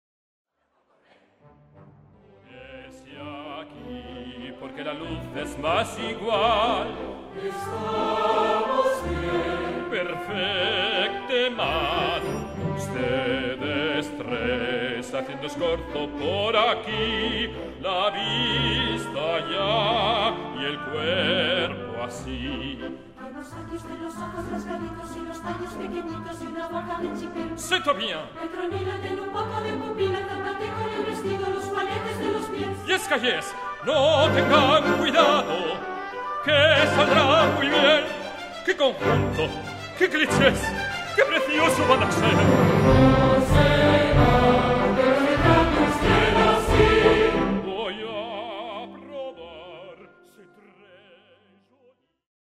Audio - Orquestra i Cors El Bateo. Tenerife